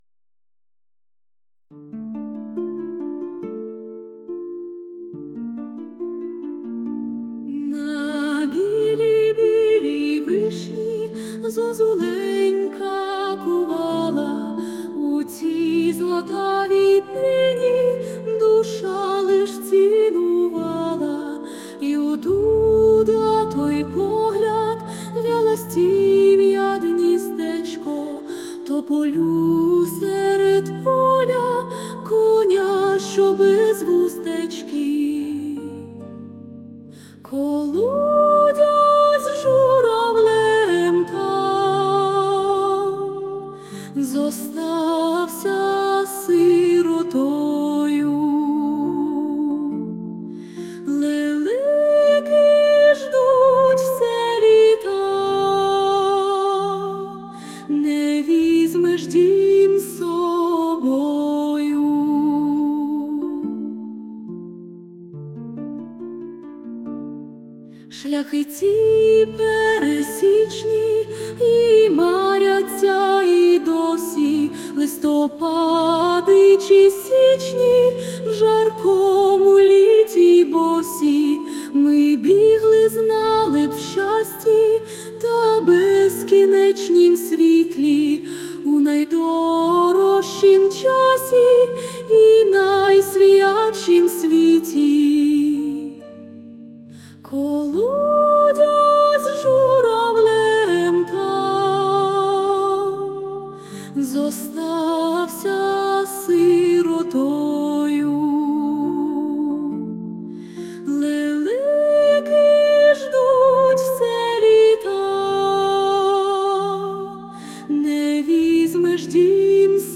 Мелодія на слова пісні:
СТИЛЬОВІ ЖАНРИ: Ліричний